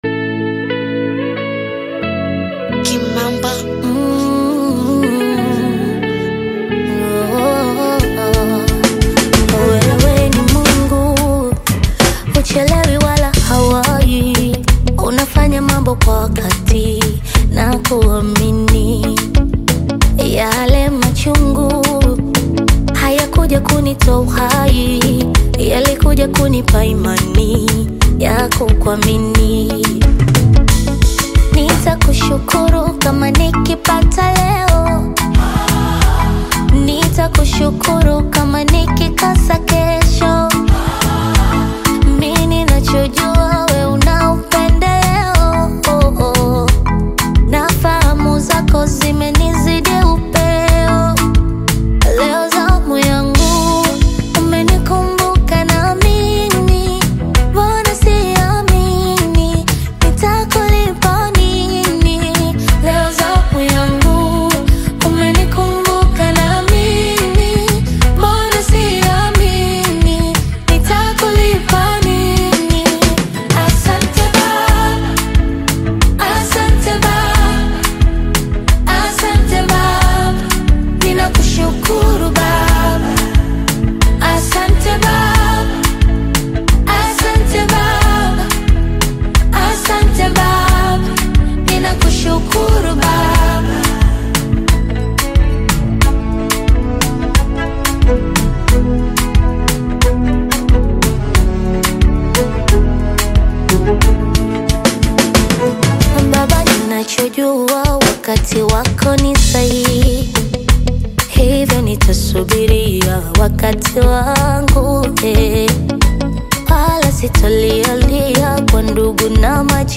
is a groovy anthem
with impressive production, catchy vibes